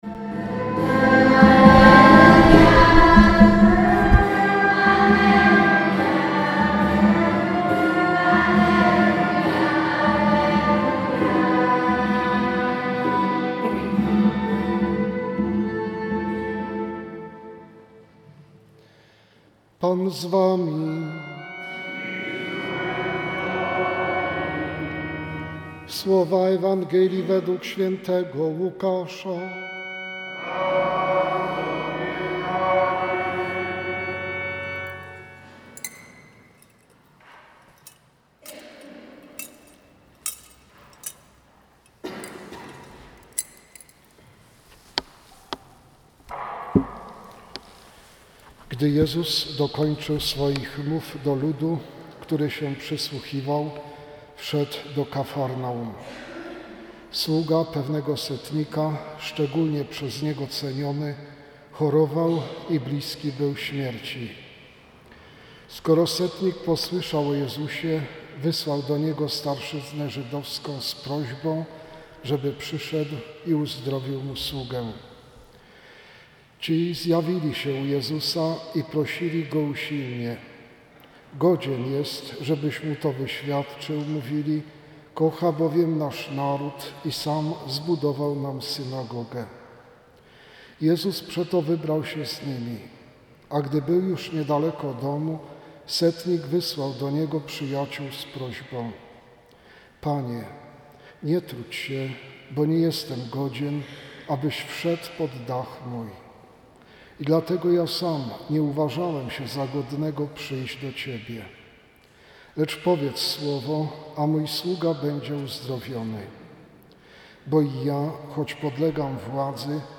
25latKazanie.mp3